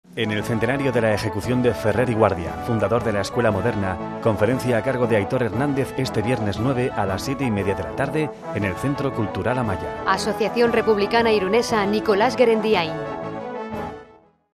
Cuñas en "Radio Irun" anunciando la presentación del libro